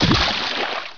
splish1.wav